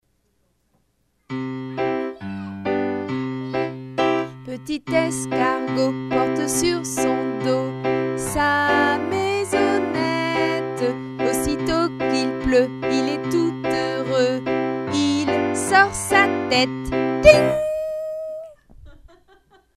is a great singer. We recorded a number of children's songs in November 2002 with myself at the keyboard.